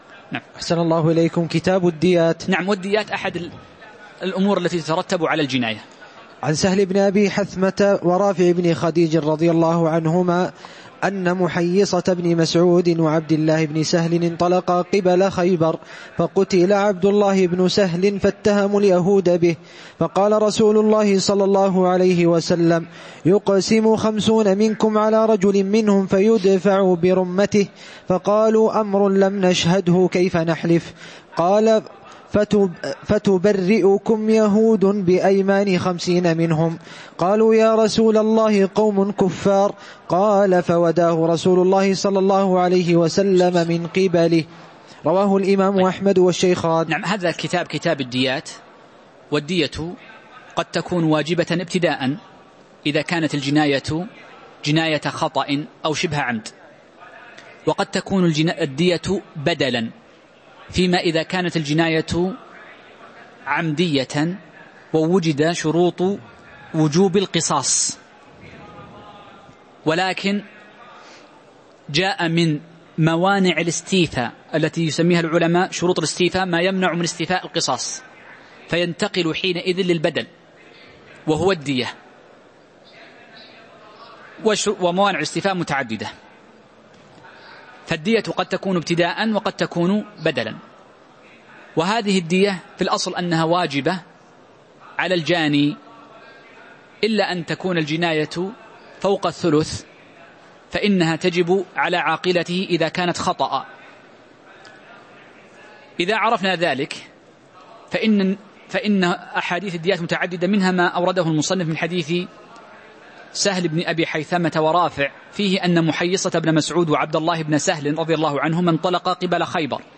تاريخ النشر ١٢ ربيع الأول ١٤٤١ هـ المكان: المسجد النبوي الشيخ